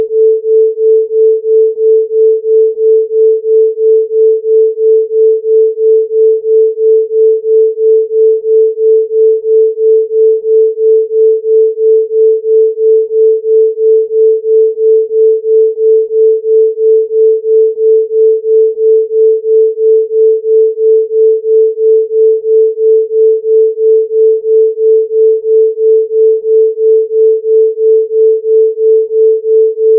18 février 2026 à 18:37 440gauche443Droite.mp3 (fichier)